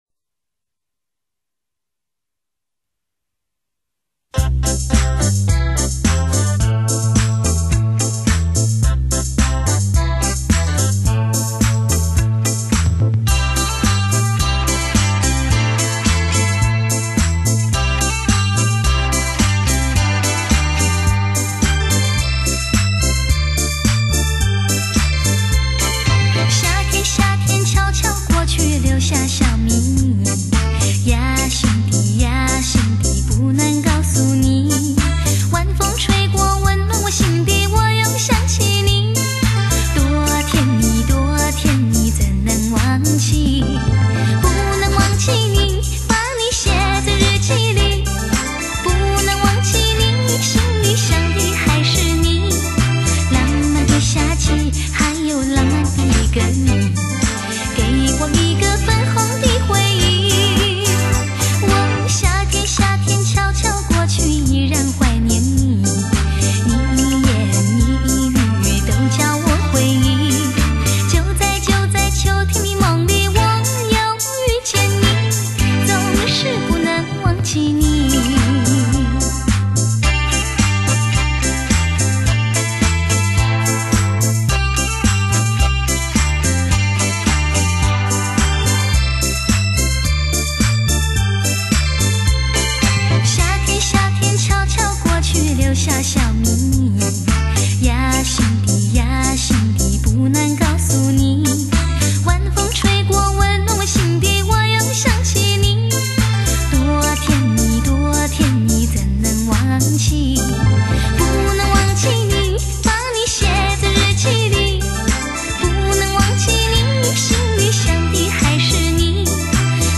甜歌大全。拨动心弦的温柔成熟心声，深情婉约的音韵，万般缠绵的柔情。